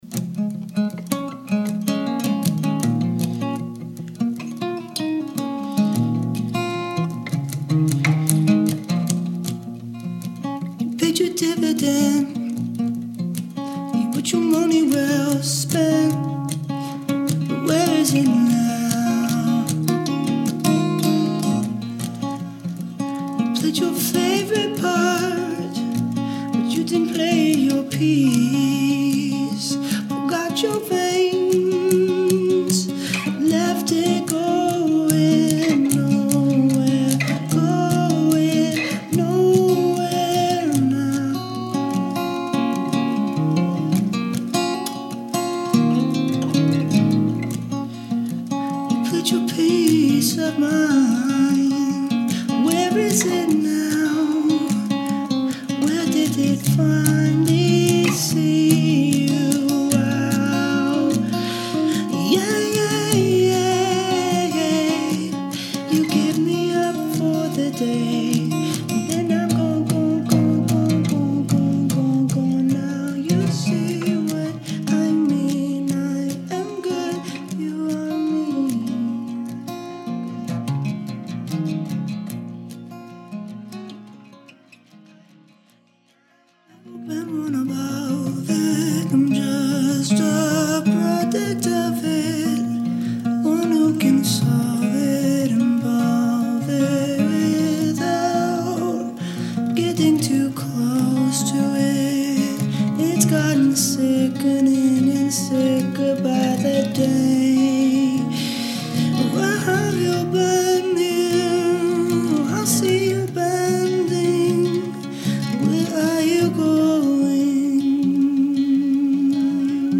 One track madness. It is organic.